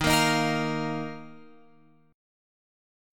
D# Suspended 2nd